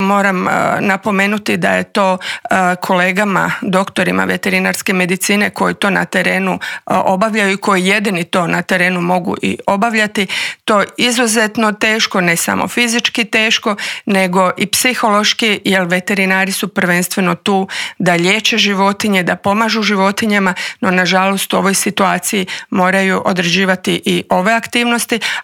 Socio-ekonomske posljedice su ogromne, a o tome kako izaći na kraj s ovom bolesti koja ne pogađa ljude u medicinskom, ali definitivno da u ekonomskom smislu, razgovarali smo u Intervjuu tjedna Media servisa s ravnateljicom Uprave za veterinarstvo i sigurnost hrane Tatjanom Karačić.